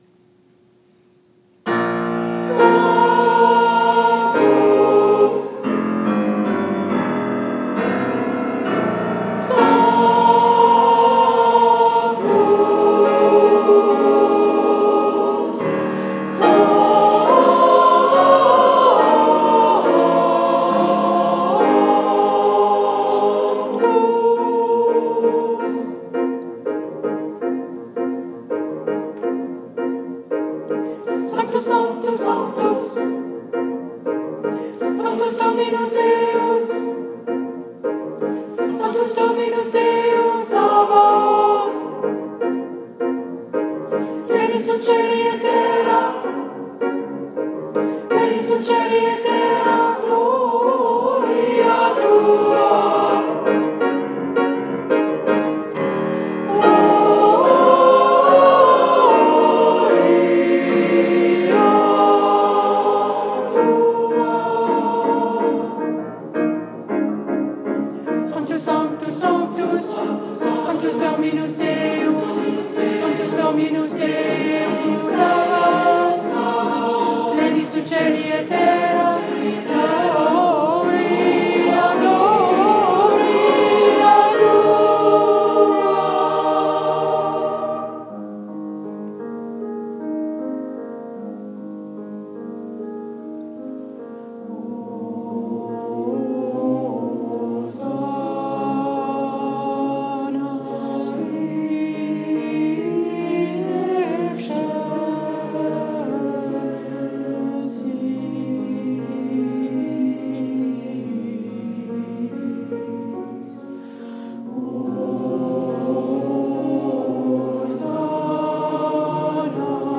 Je vous mets aussi un enregistrement (fait par moi-même) d’un morceau que qu’aimais un peu plus, mais on n’entend pratiquement que les altos, donc c’est moyennement intéressant…